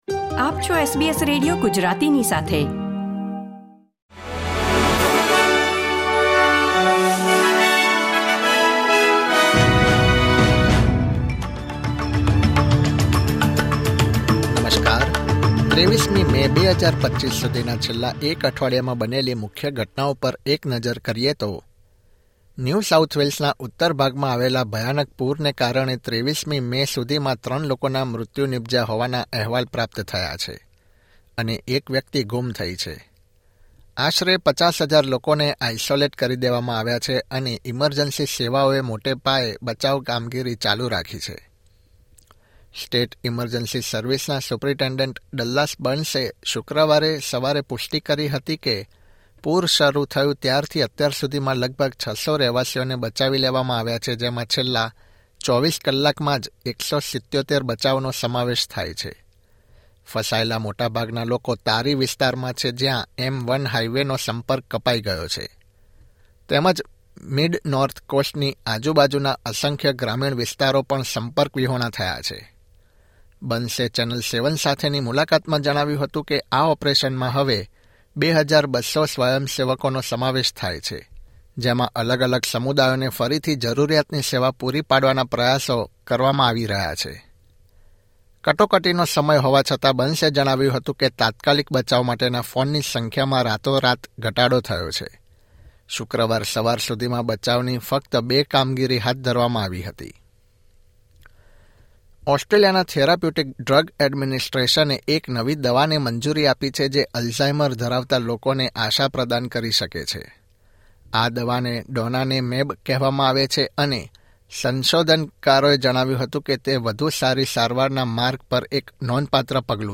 Weekly news update from Australia